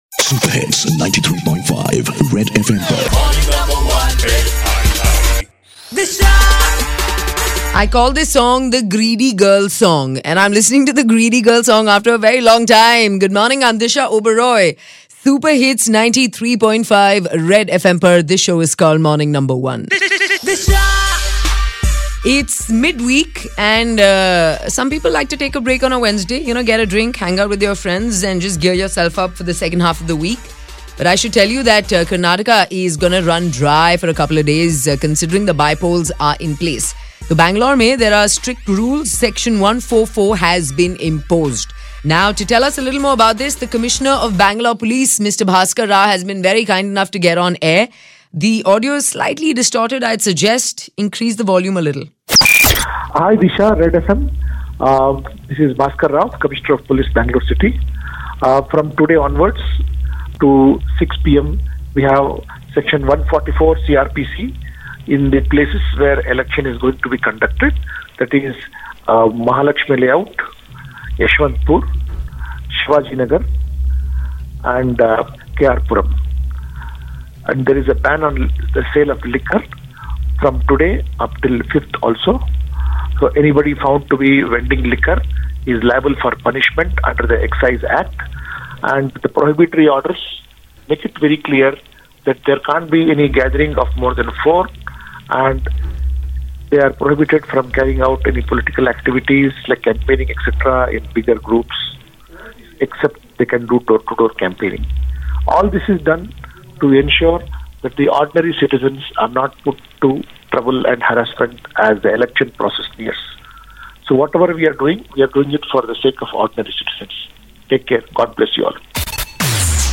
Section 144 ib Bengaluru due to Bypolls in four Areas- Mr. Bhaskar Rao, Commissioner of Police, Bangalore gives valuable information